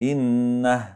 receteras med sukun när man stannar upp på det